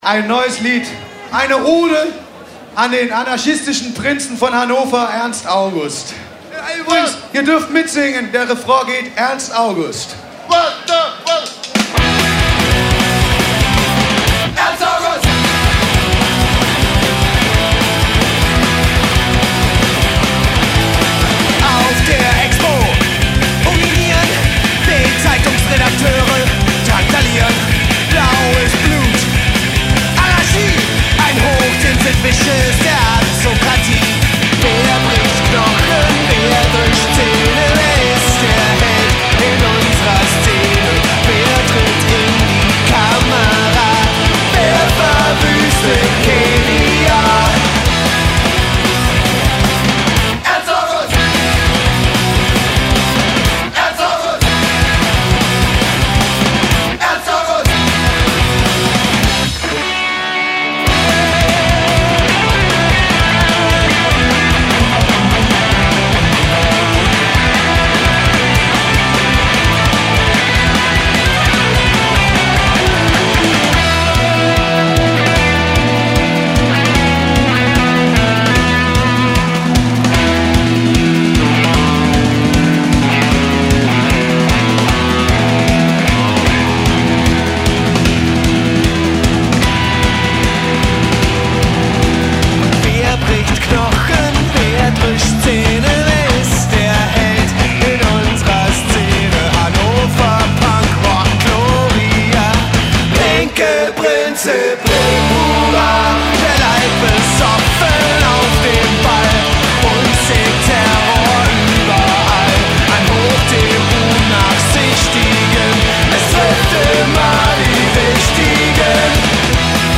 il più famoso gruppo punkrock della Tedeschia...